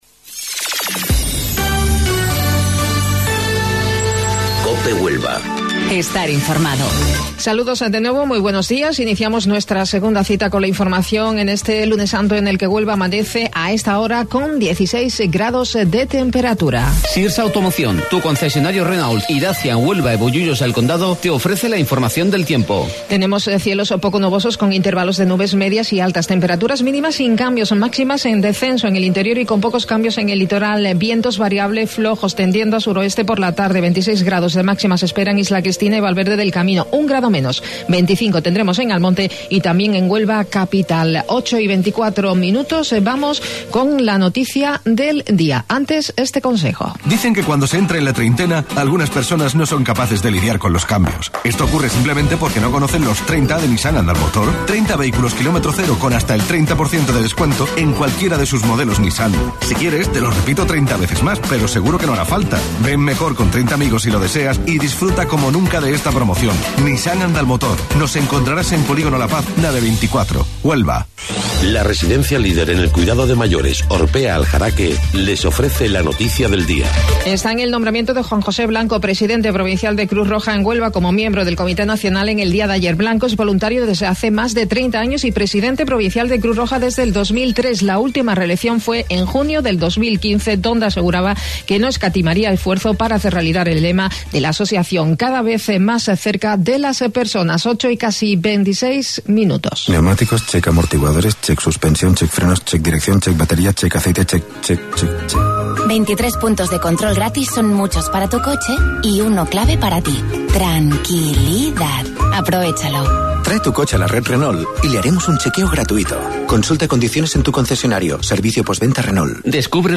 AUDIO: Informativo Local 08:25 del 15 de Abril